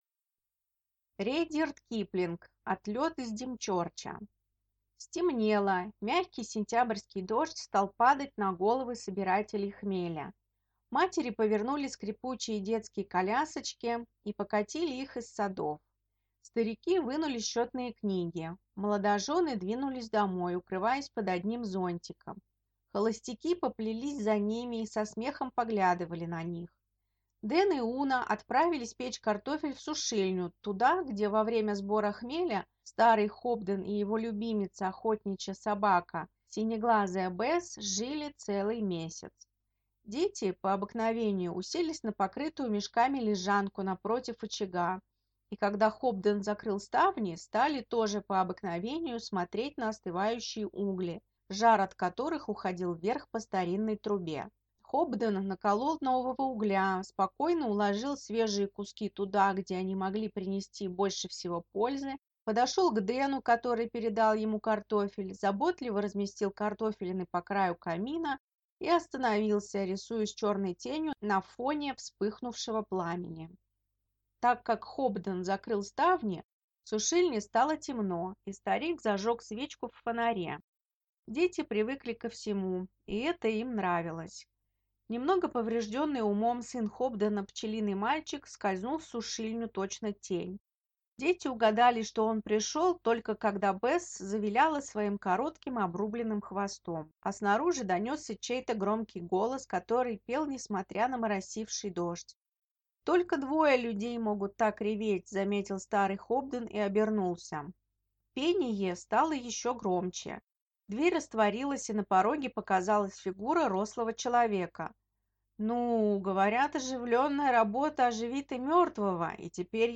Аудиокнига Отлет из Димчерча | Библиотека аудиокниг